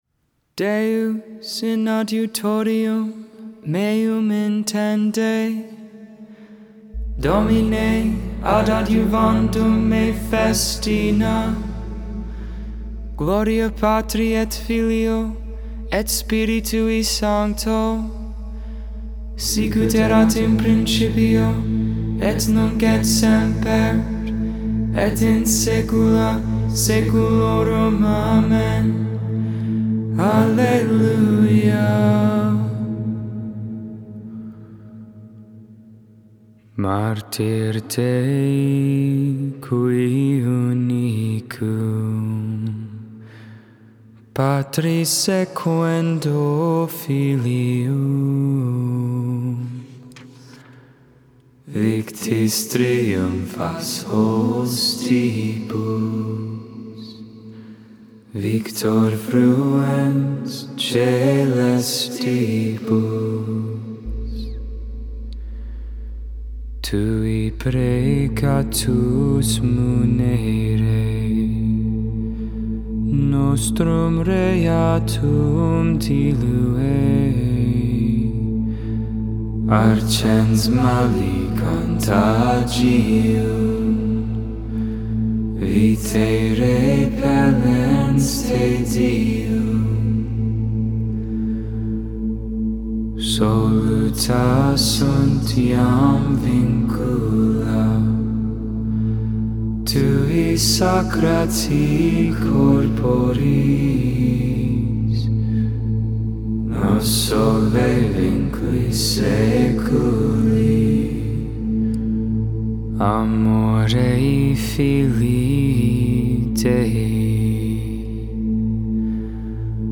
Psalm 116v1-9 (StH Eastern tone#2)
Canticle: Revelation 4v11; 5v9, 10, 12 (tone 4)